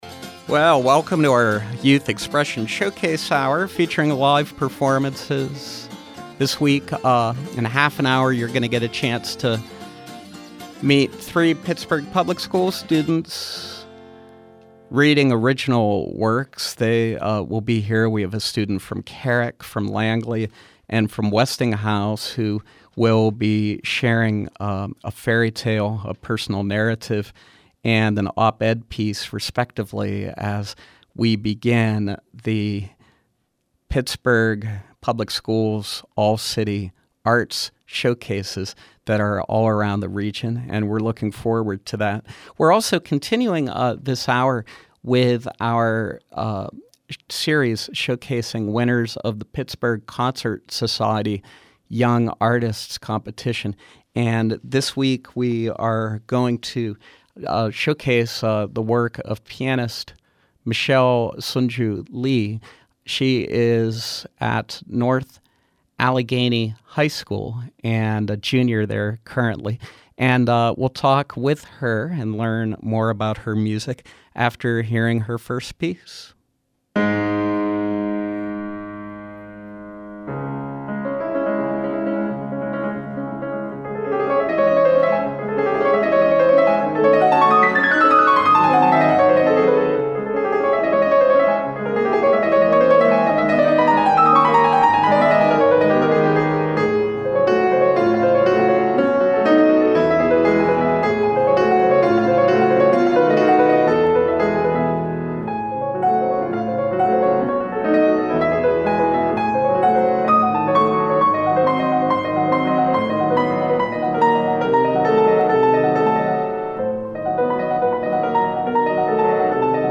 Pittsburgh Concert Society’s Young Artist Winner’s Showcase
works for piano